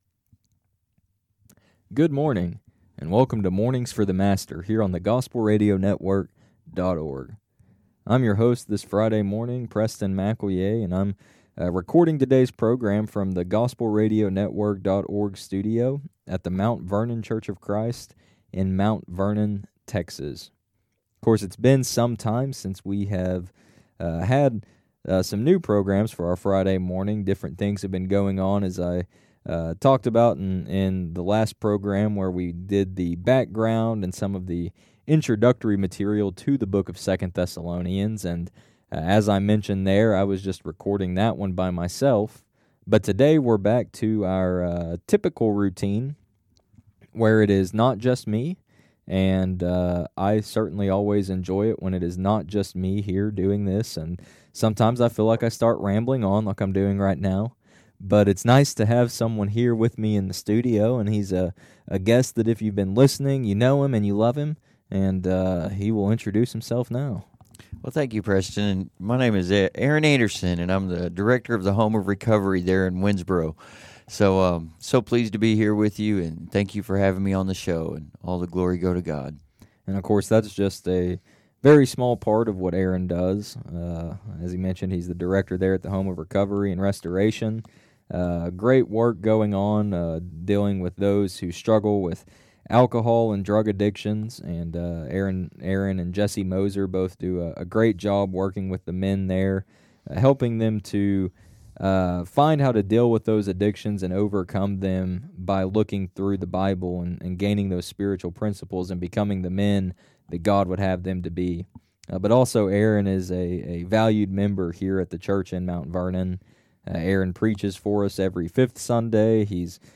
Program Info: Live program from the TGRN studio in Mount Vernon, TXClick here for current program schedule.